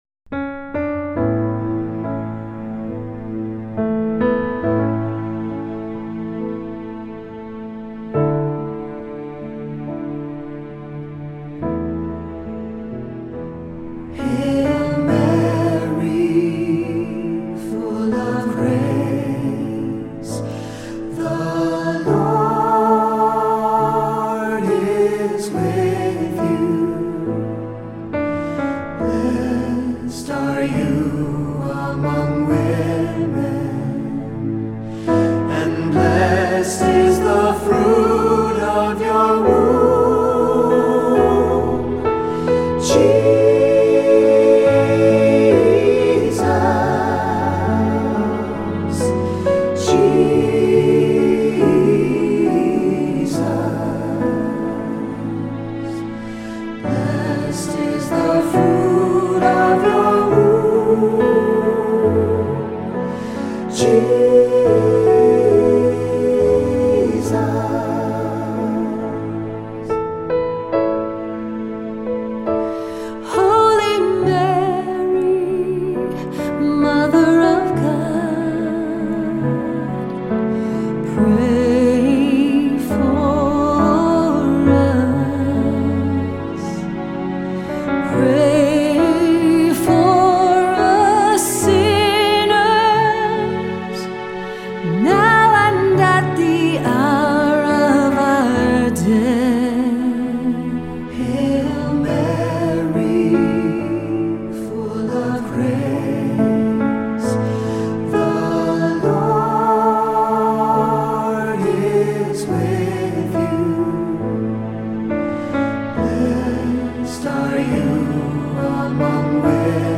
Voicing: Assembly, cantor,SATB